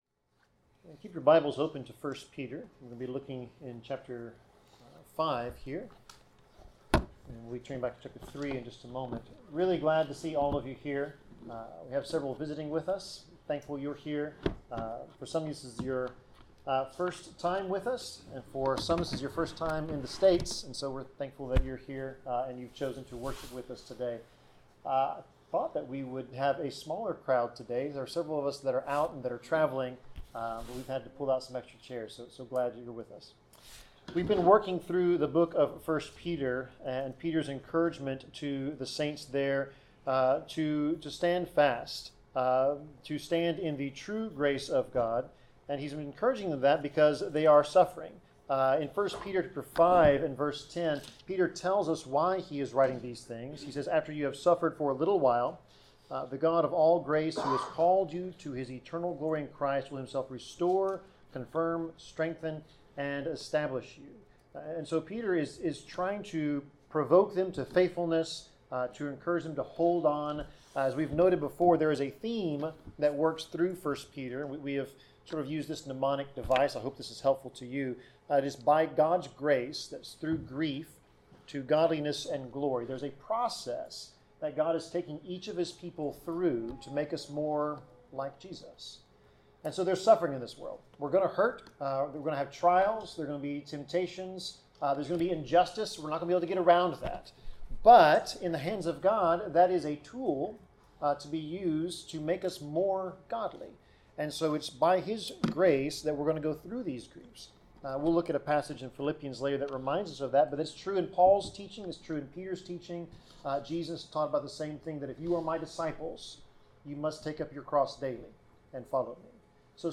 Passage: 1 Peter 3:8-4:11 Service Type: Sermon